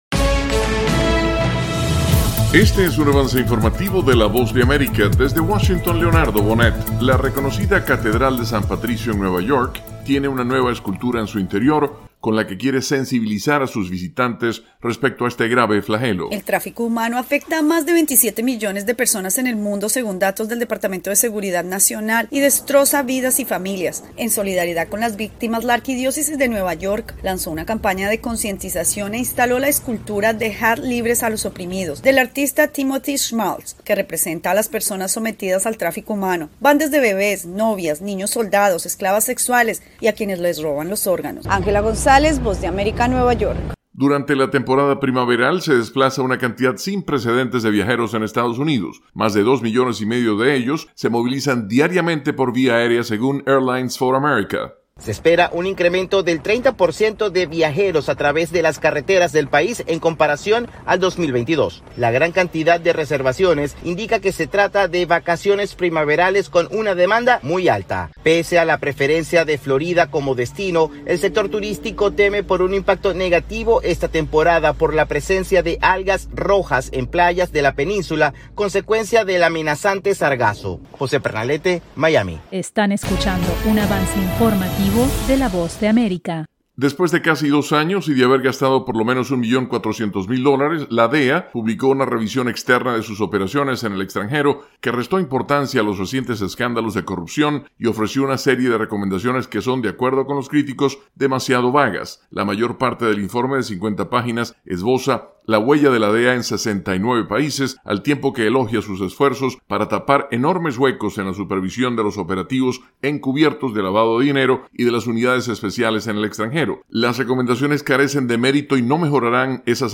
Avance Informativo 7:00 PM